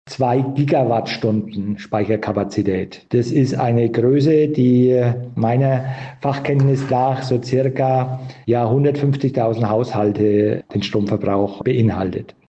Wir sprechen mit Ulrich Werner, dem Bergrheinfelder Bürgermeister.